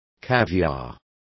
Also find out how caviar is pronounced correctly.